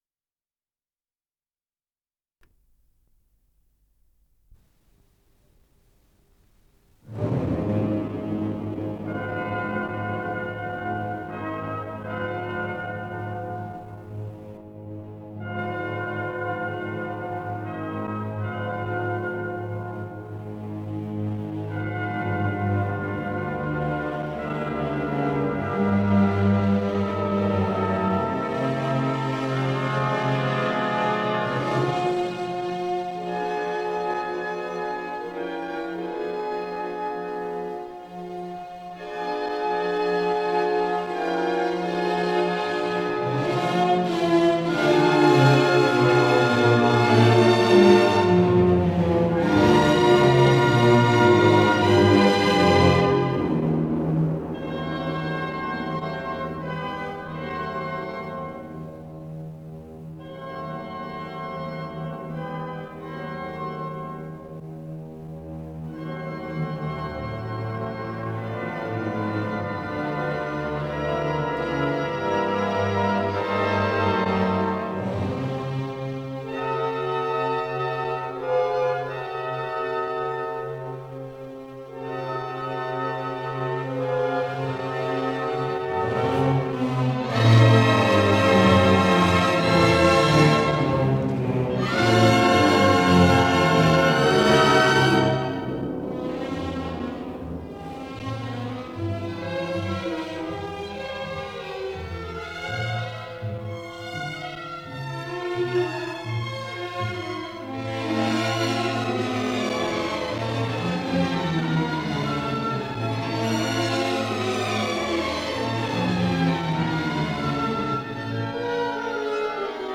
Исполнитель: Государственный симфонический оркестр СССР
Симфоническая фантазия
ми минор